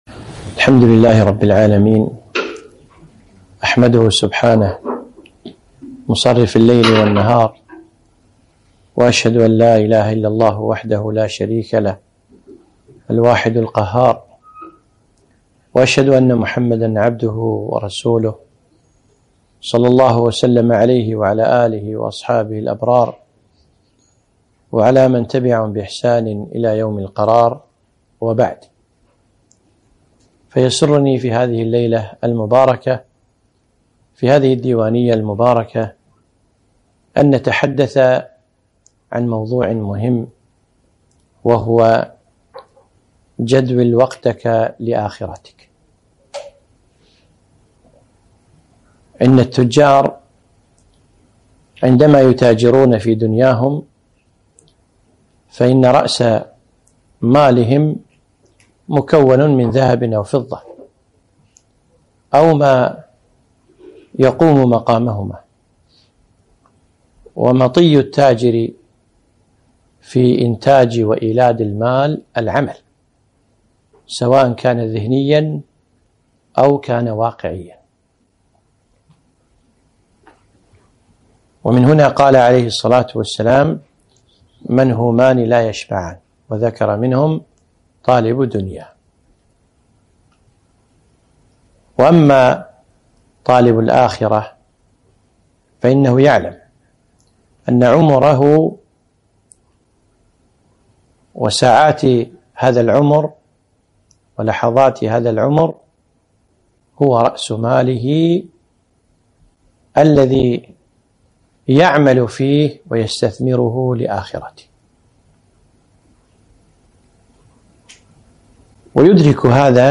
محاضرة - جدول وقتك لأخرتك